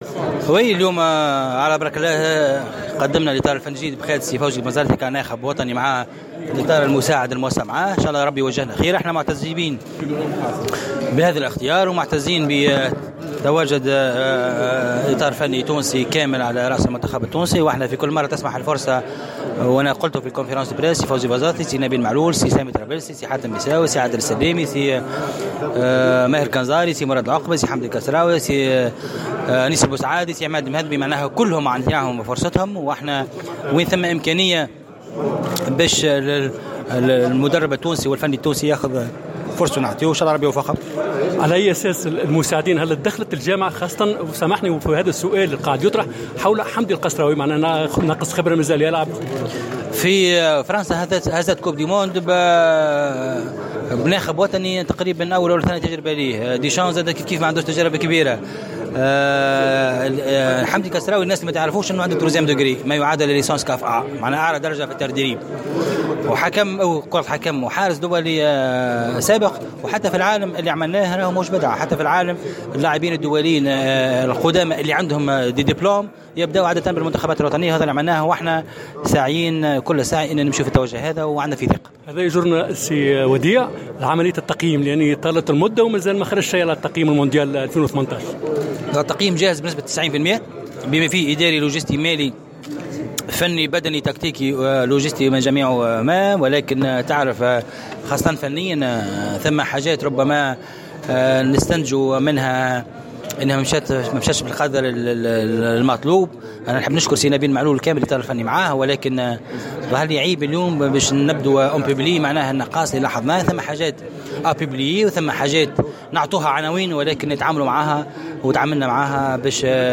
تحدث رئيس الجامعة التونسية لكرة القدم وديع الجريء خلال الندوة الصحفية المنعقدة اليوم الثلاثاء 07 أوت 2018 ، لتقديم الإطار الفني الجديد بقيادة فوزي البنزتي ، عن بعض التفاصيل التي تخص المشاركة في نهائيات كأس العالم روسيا 2018.